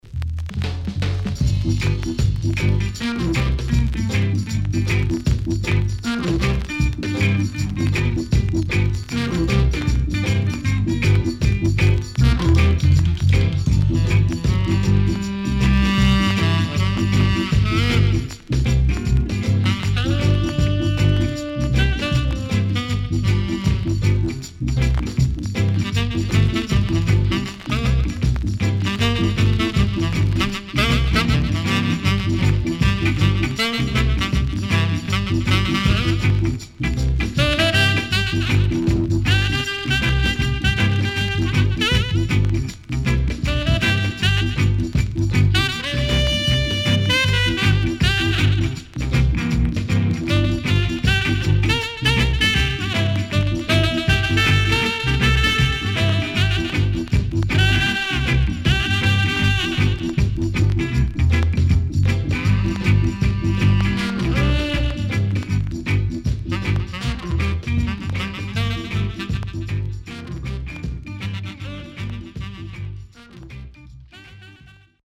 EARLY REGGAE
CONDITION SIDE A:VG(OK)
SIDE A:所々チリノイズがあり、少しプチノイズ入ります。